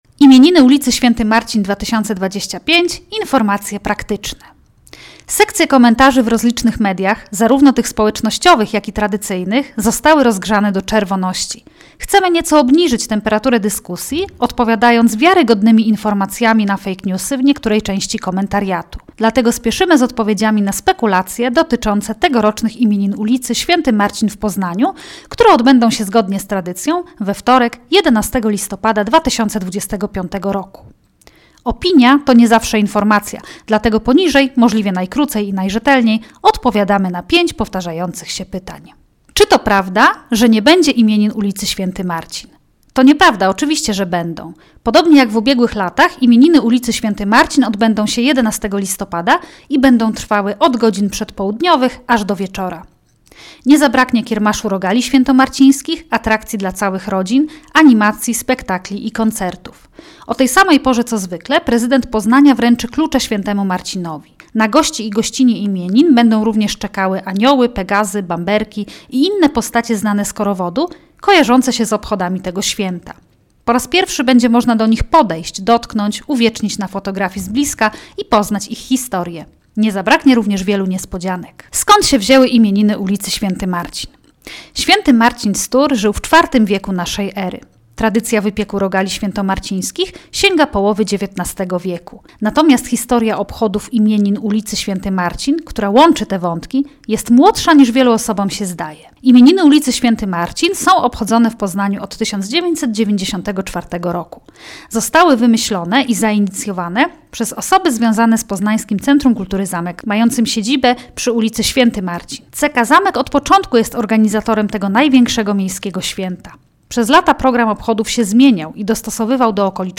AUDIOULOTKA
Informacje_praktyczne_audioulotka_MP3.mp3